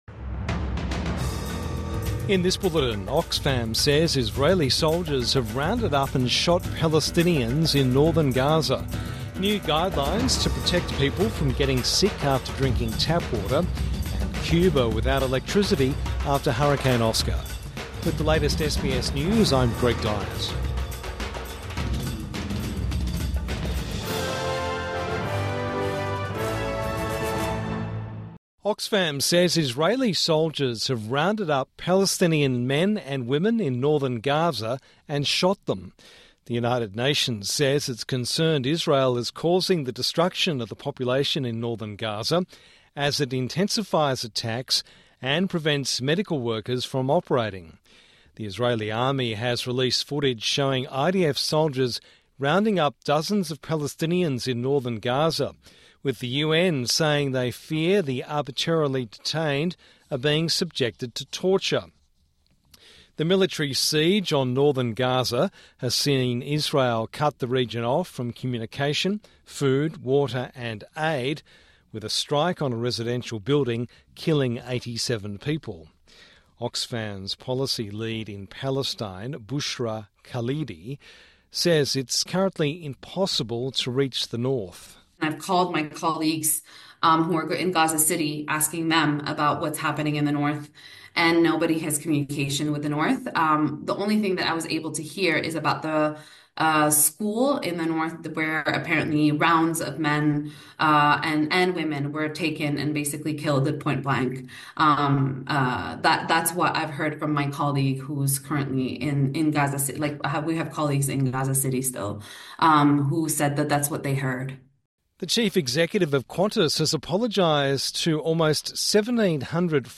Evening News Bulletin 21 October 2024